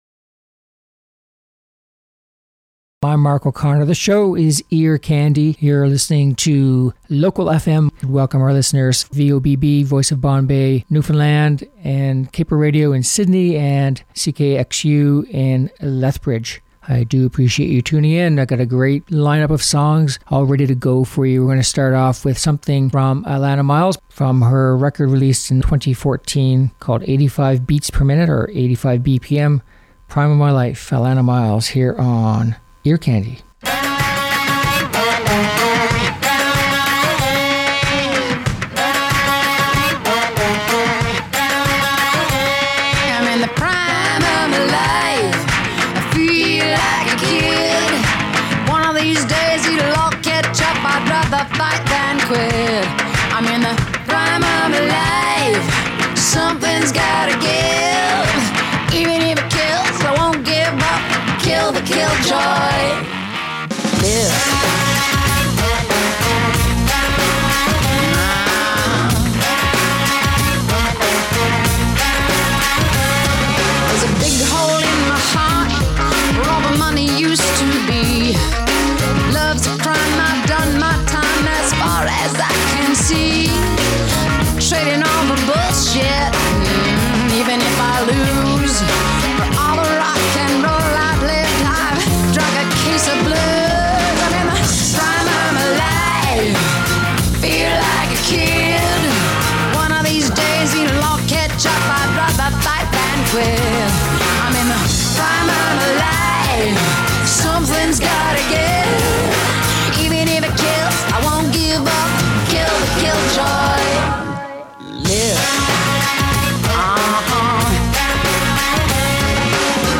Upbeat Pop Songs